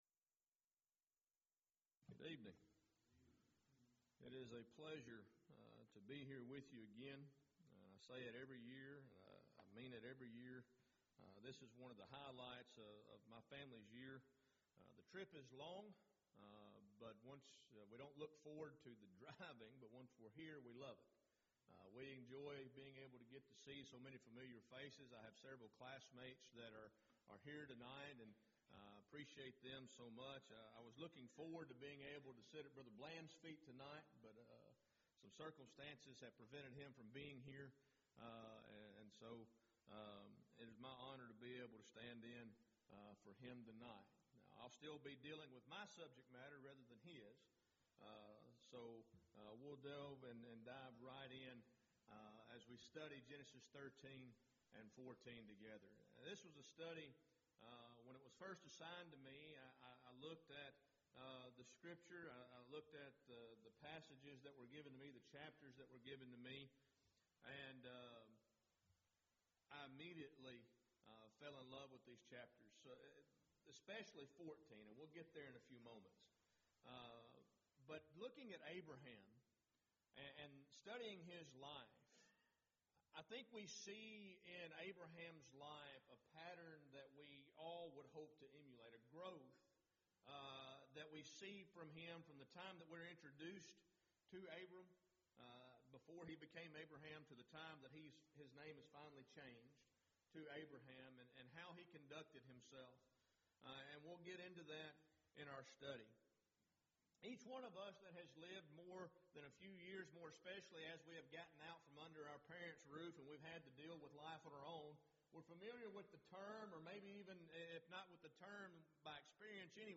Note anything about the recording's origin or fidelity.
16th Annual Schertz Lectures